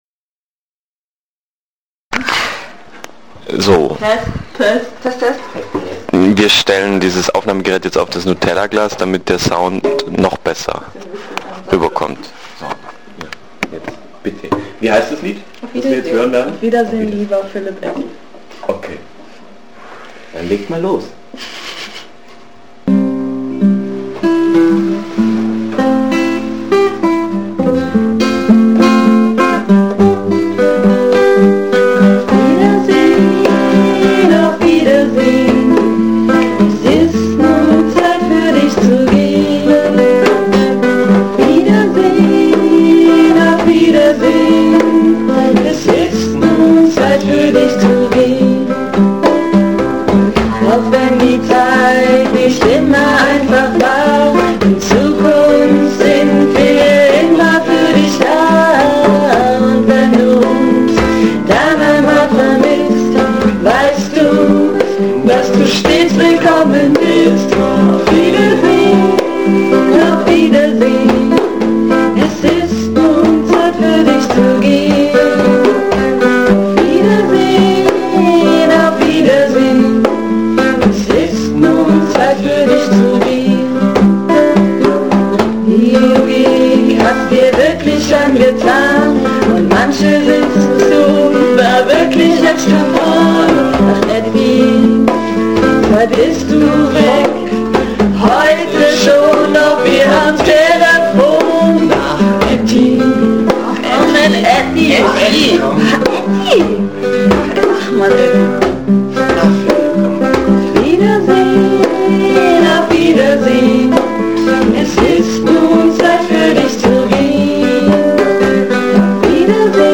Live!